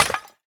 Minecraft Version Minecraft Version snapshot Latest Release | Latest Snapshot snapshot / assets / minecraft / sounds / block / decorated_pot / shatter5.ogg Compare With Compare With Latest Release | Latest Snapshot
shatter5.ogg